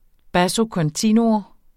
Udtale [ ˌbaso kʌnˈtiˀnuo ]